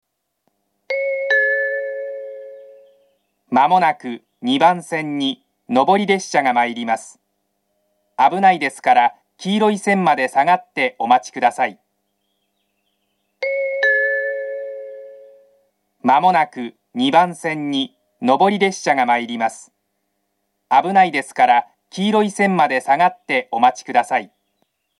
２番線上り接近放送 このホームからの旅客列車の発車はありません。